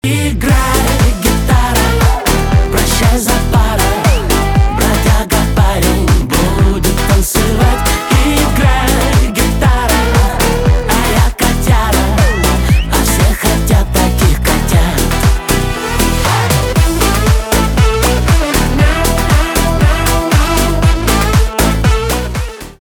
поп
басы , труба
позитивные , гитара